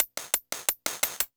Index of /musicradar/ultimate-hihat-samples/175bpm
UHH_ElectroHatC_175-01.wav